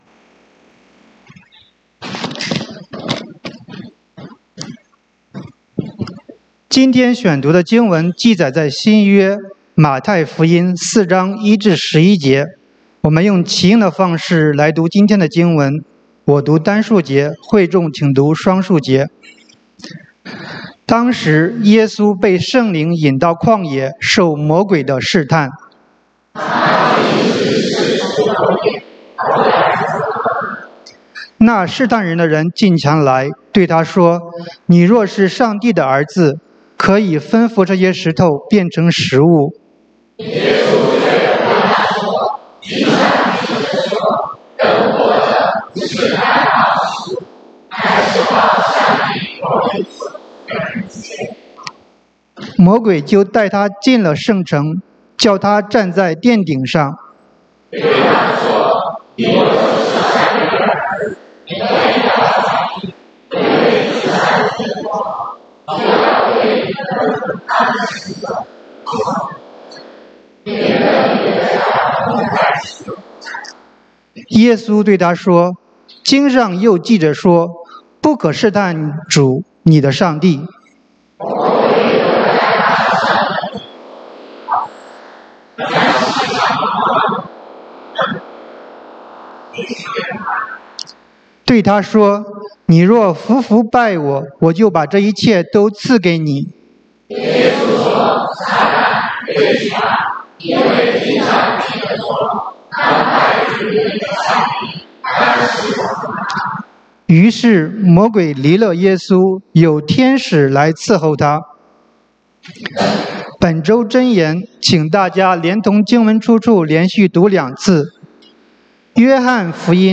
3/1/2026 講道經文：馬太福音 Matthew 4:1-11 本週箴言：約翰福音 John 16:33 耶穌說：「我將這些事告訴你們，是要叫你們在我裡面有平安。